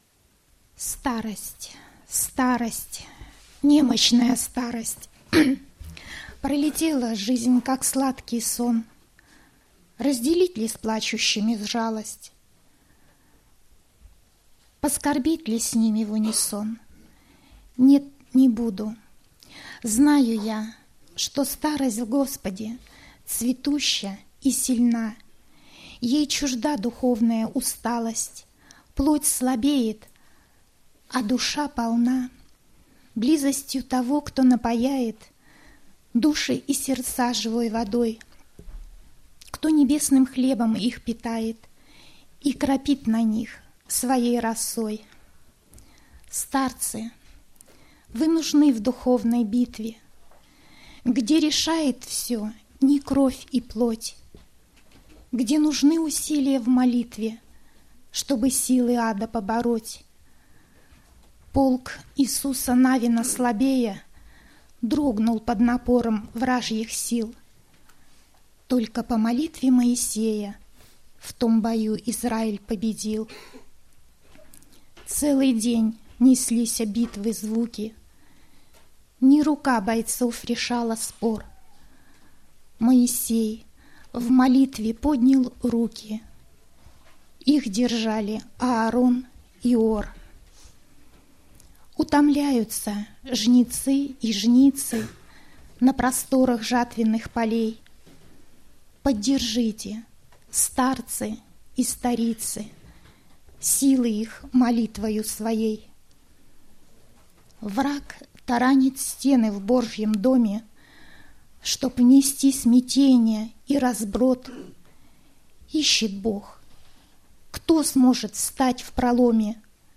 Богослужение 12.09.2010 mp3 видео фото
Стихотворение